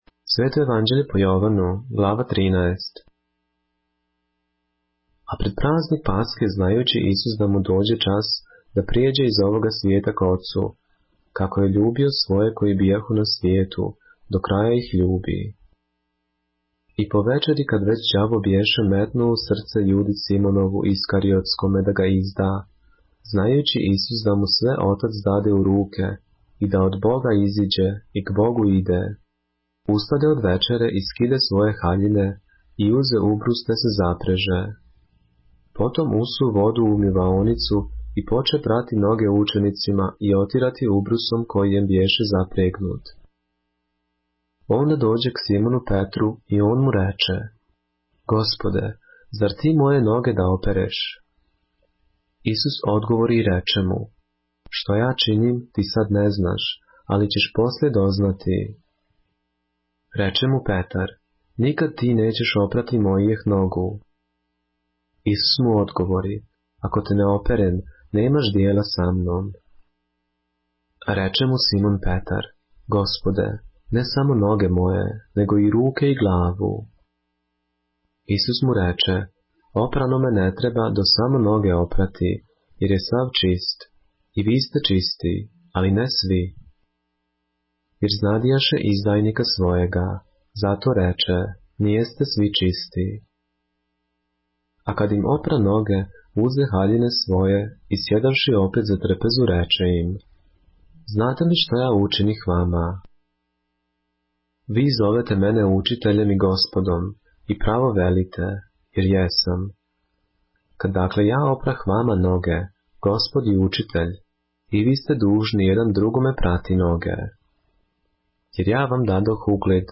поглавље српске Библије - са аудио нарације - John, chapter 13 of the Holy Bible in the Serbian language